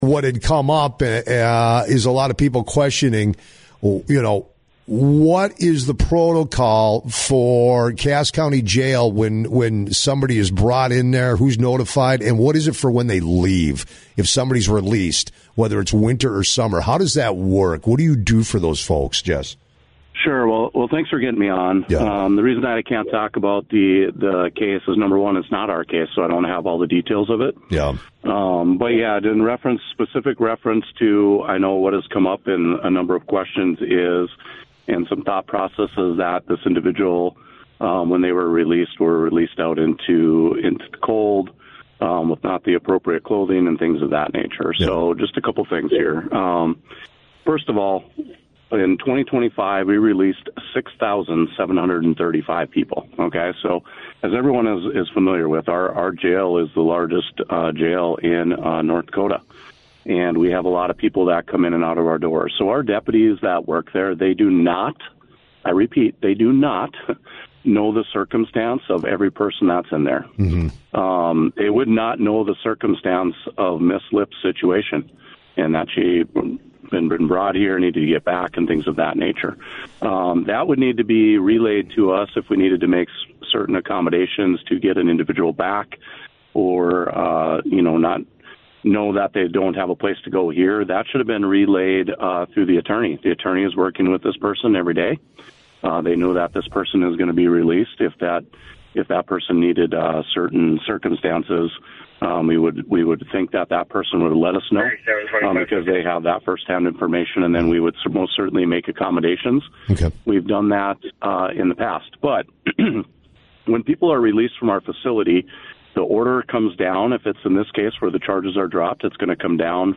jahner-on-jail-full-interview.mp3